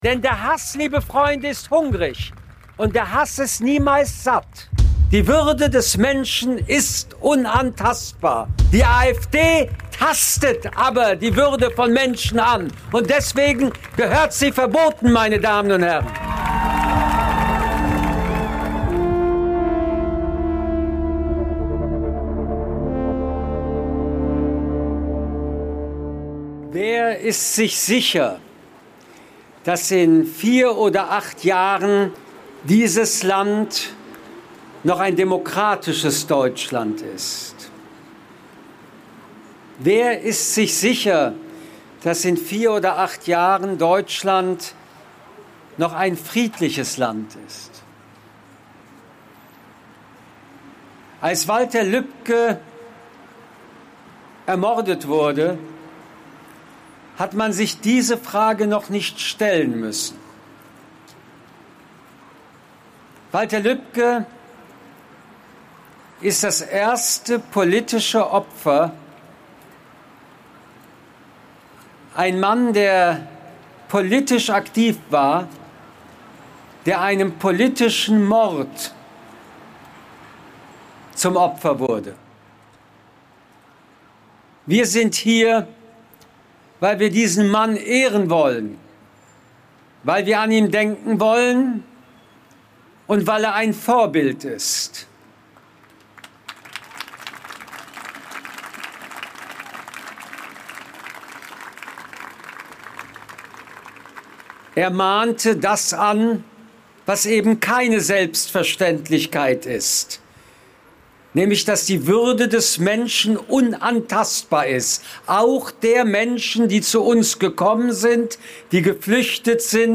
Episode #3 - Sondersendung - Michel Friedman: Jahrhundertrede auf die Demokratie am Walter Lübcke Memorial ~ Zeitsturz Podcast